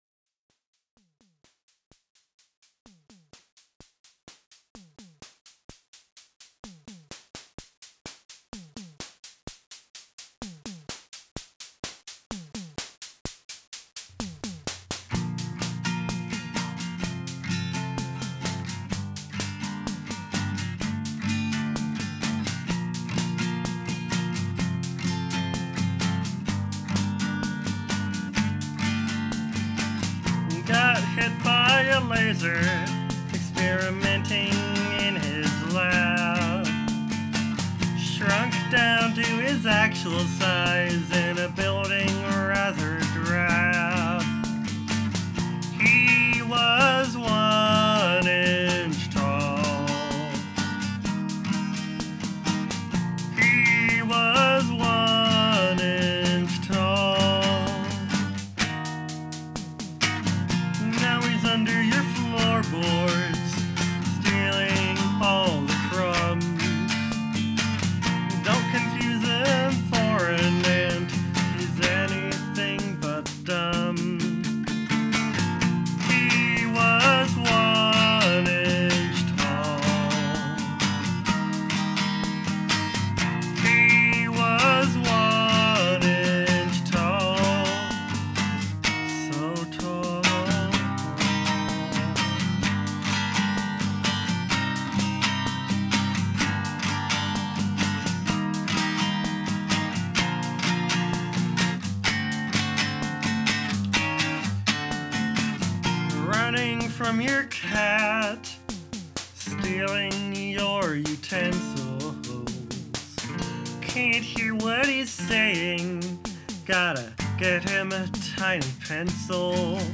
oh yeah!! also wait one more thing. since the last time i posted here i got a drum machine and it's so cool, it's the Mattel Synsonics which is i think probably the last cheap analog drum machine. this comes at the caveat of it being super primitive, but it's still a cool thing i like toying with.